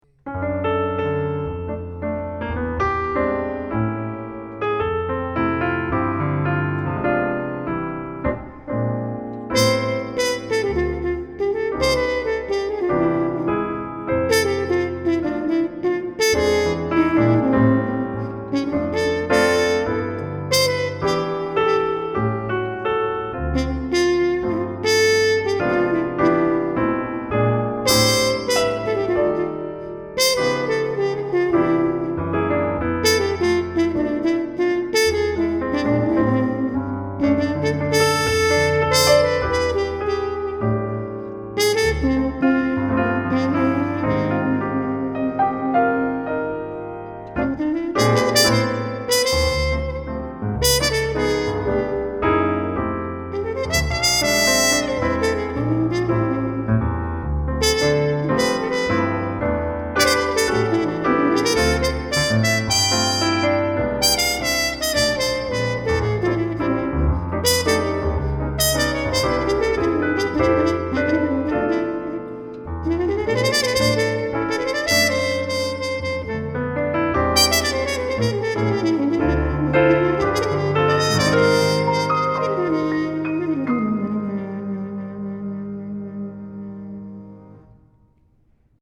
(piano)
jazz waltz
trumpet - vibes - cello - viola